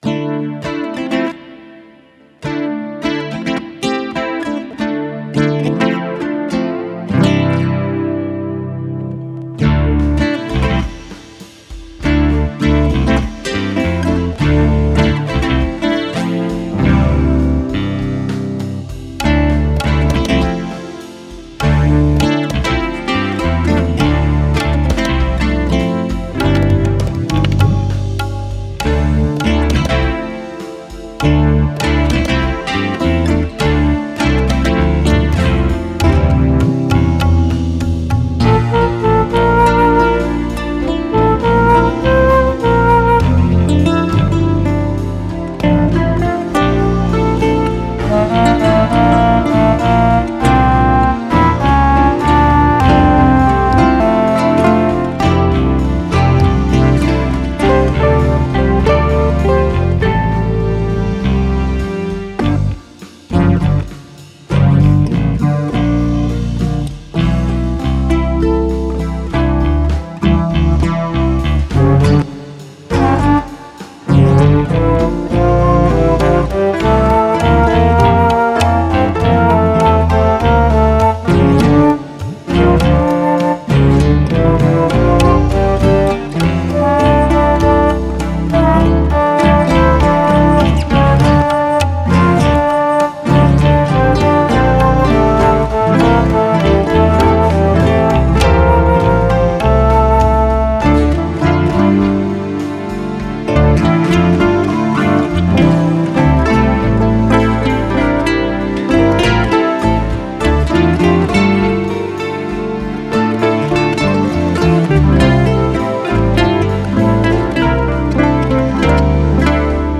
Als Suite gehen die Stücke ineinander über und sind in einen gemeinsamen Spannungsbogen eingebunden.
Musikalisch kommen arabische, indische und japanische Skalen zum Einsatz. Die klangliche Einfärbung ist indisch, die Komposition jedoch westlich, sodass die Schublade Ethno-Fusion-Rock am besten passt.